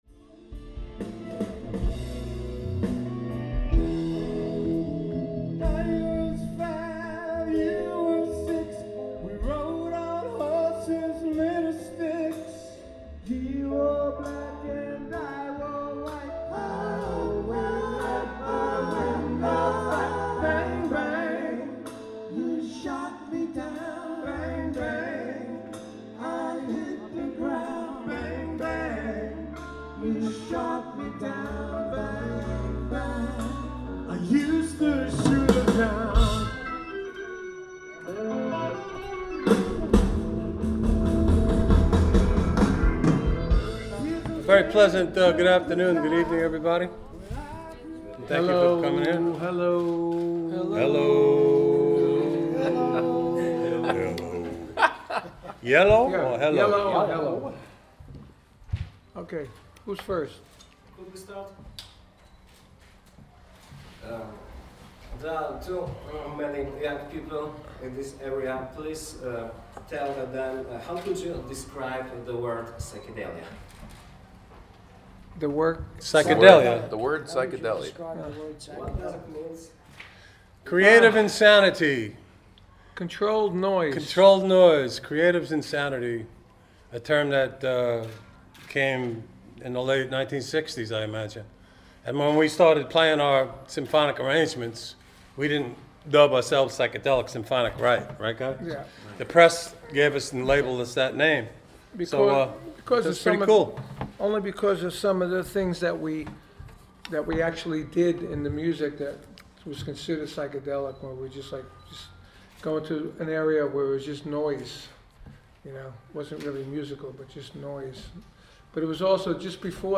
Rock psychodeliczny